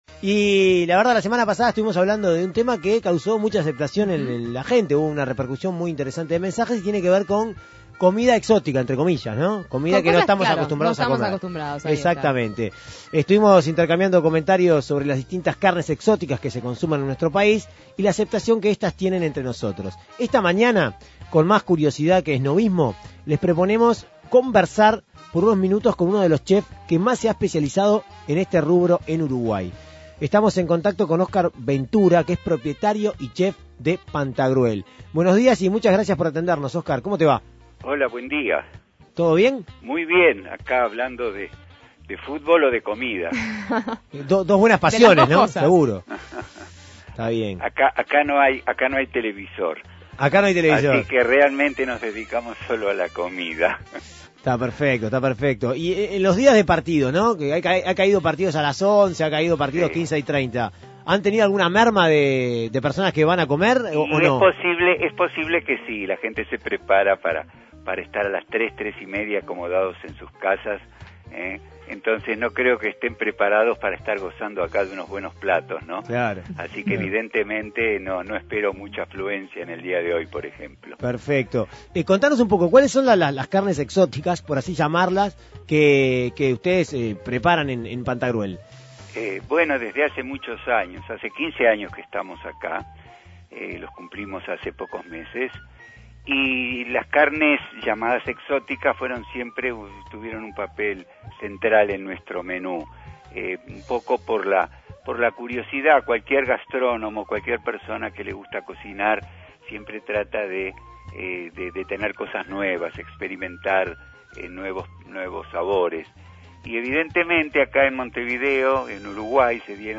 Con más curiosidad que esnobismo, la Segunda Mañana de En Perspectiva conversó con uno de los chefs que más se ha especializado en la preparación de carnes exóticas.